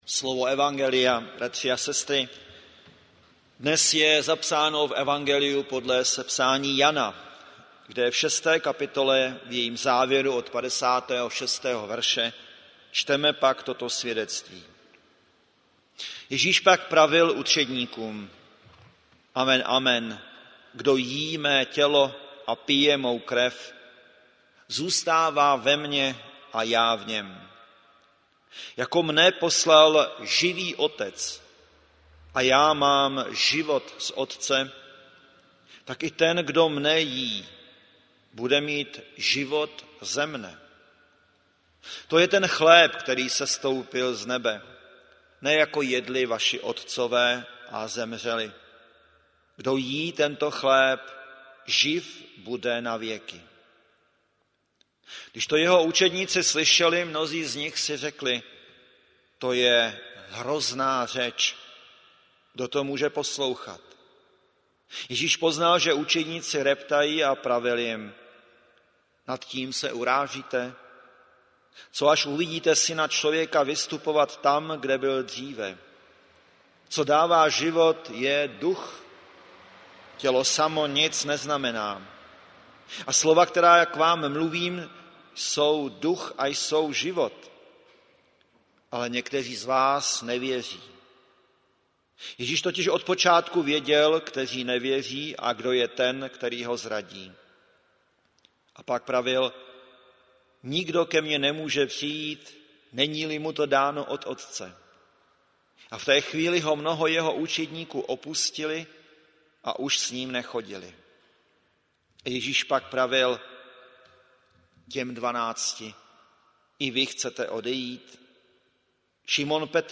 Záznam kázání a sborová ohlášení z bohoslužeb konaných dne 26.8.2018 v evangelickém chrámu Mistra Jana Husi v Plzni.